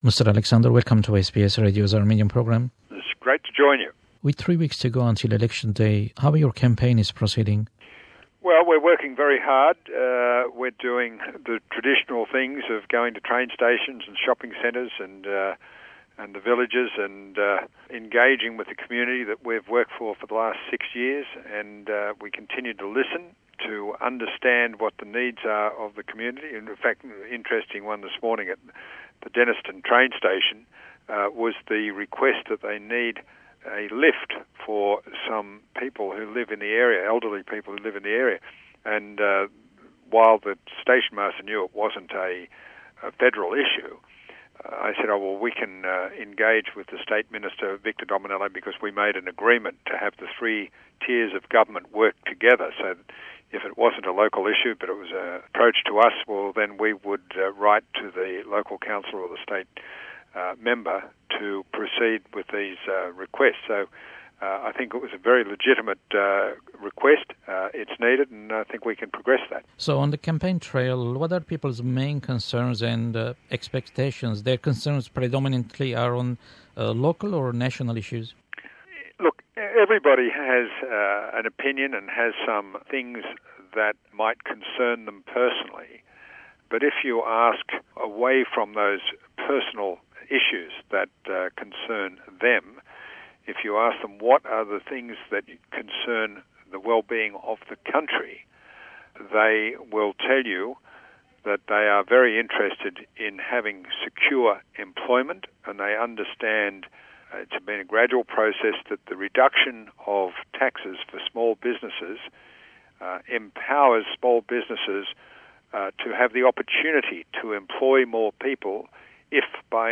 An interview with Mr John Alexander OAM, MP, Member for Bennelong, New South Wales.